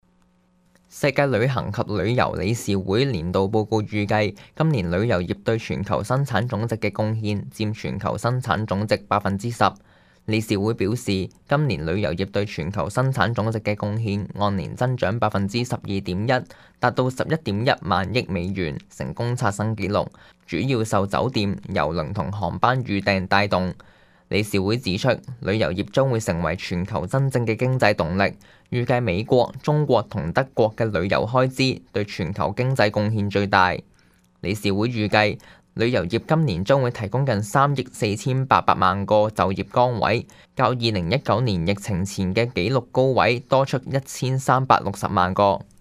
news_clip_20411.mp3